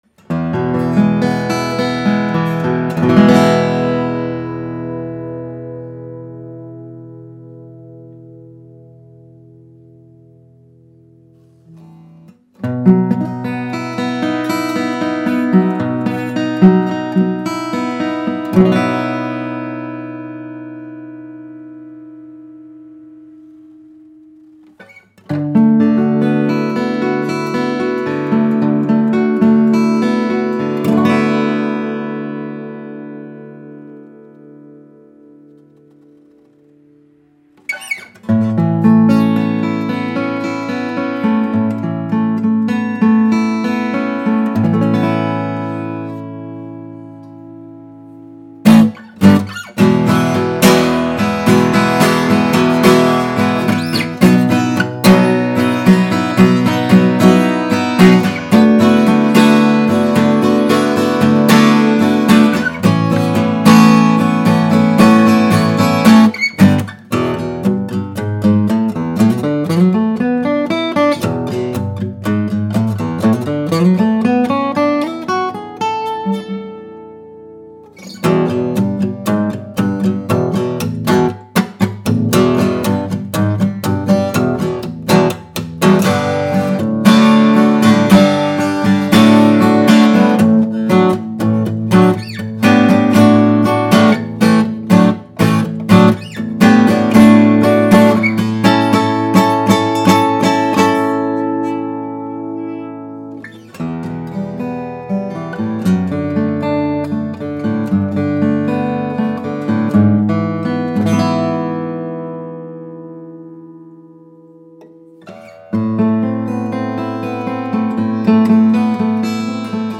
Spectacular sound. Huge voice.
The Madagascar Rosewood used for the back and sides of this dreadnought provides a rich, resonant tone with a balanced response. Adirondack Red Spruce adds clarity and dynamic range, enhancing the guitar’s overall sound. This combination of woods is favored for its ability to produce a powerful yet nuanced tone.
Martin-D-14-Custom.mp3